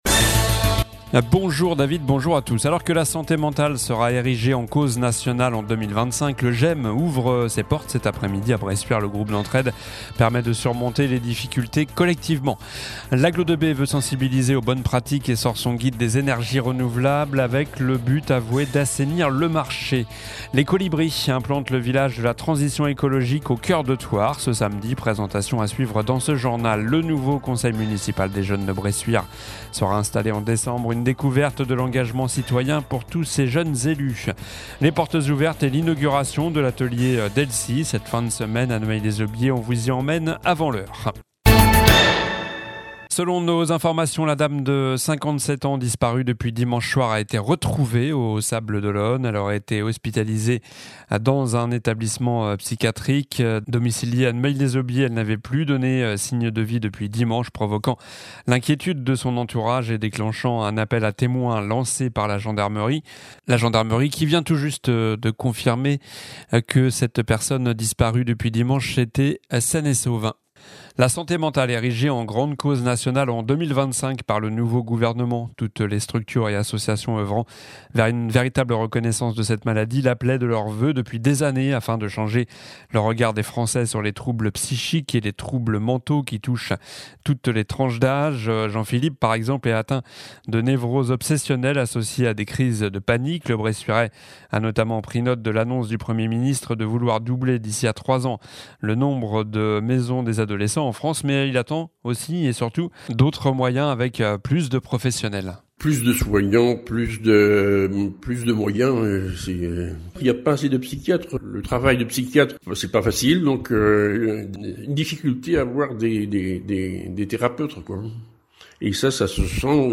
Journal du jeudi17 octobre (midi)